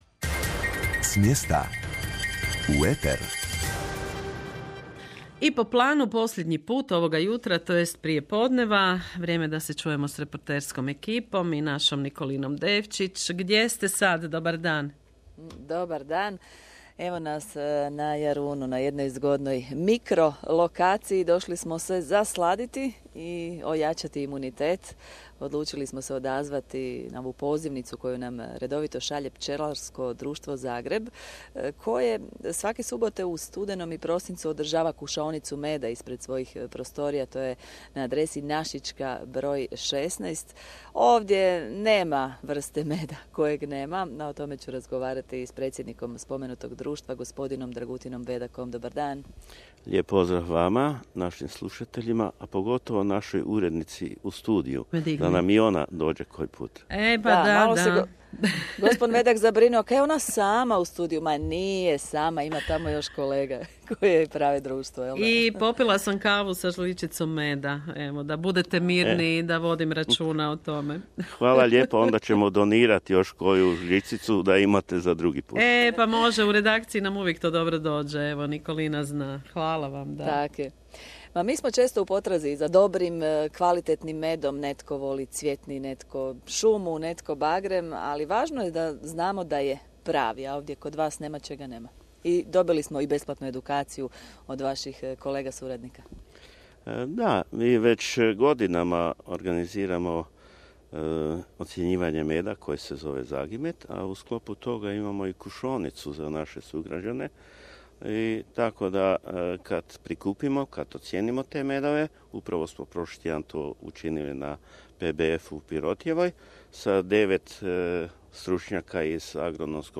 kratki intervju
snimljen na Kušaonici u subotu 8. studenog 2025.